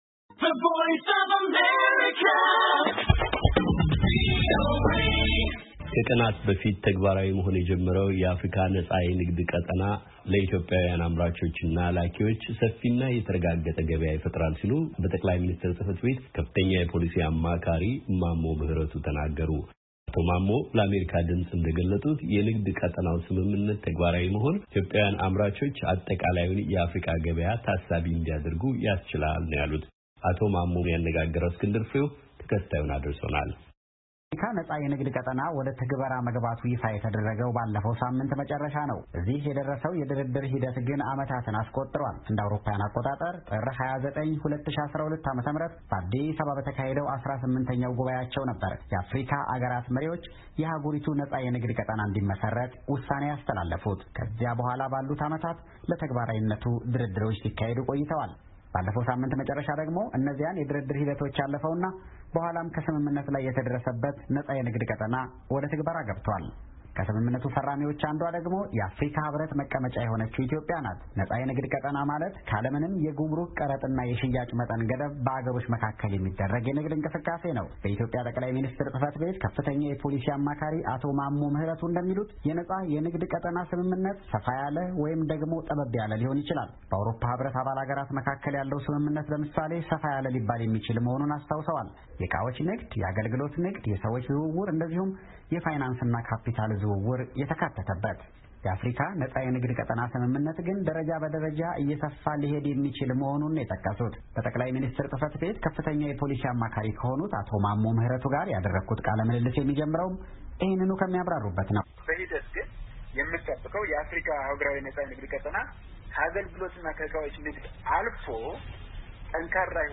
አቶ ማሞ ለአሜሪካ ድምፅ እንደተናገሩት የንግድ ቀጠናው ስምምነት ተግባራዊ መሆን በራሱ ኢትዮጵያውያን አምራቾች አጠቃላዩን የአፍሪካ ገበያ ታሳቢ እንዲያደርጉ ያስችላል። በጠቅላይ ሚኒስትር ፅ/ቤት ከፍተኛ የፖሊሲ አማካሪ የሆኑትን አቶ ማሞ ምህረቱን አነጋግረናቸዋል።